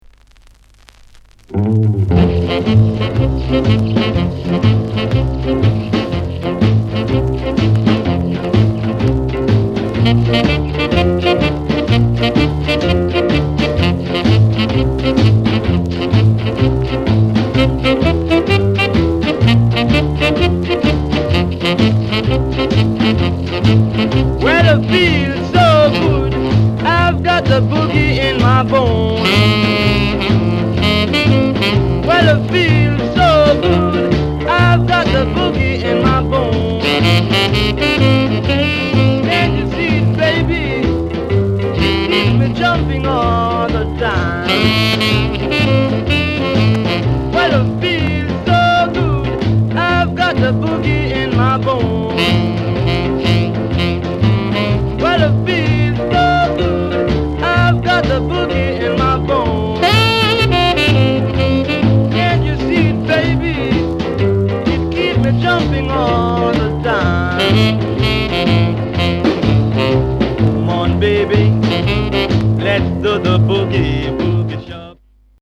SOUND CONDITION A SIDE VG